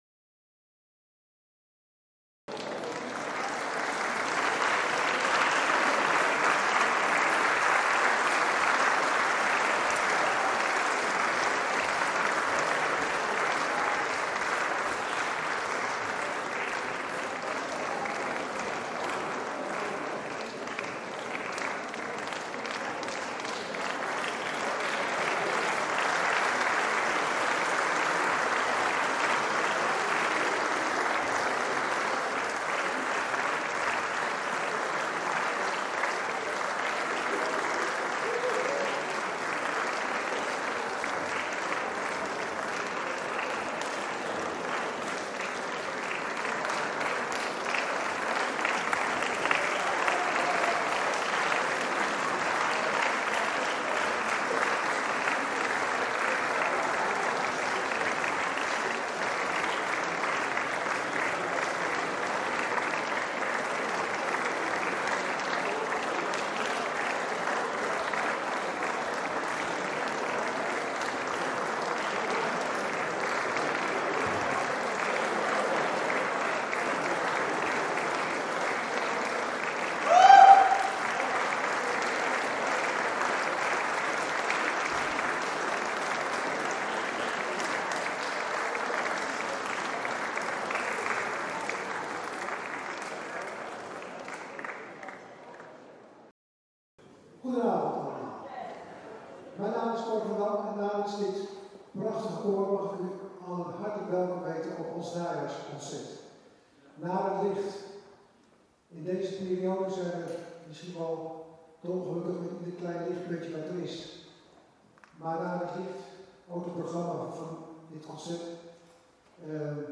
Scherzando gemengd koor Driebergen
Opnamen van concert 8 november 2025 De integrale audio opname van ons concert is hier beschikbaar. Naar het Licht download 1:16:08 Opnamen van concert 29 maart 2025 Er zijn enige korte videofragmenten uit ons concert American Blend op de fotopagina gepubliceerd en daar te te bekijken en te beluisteren.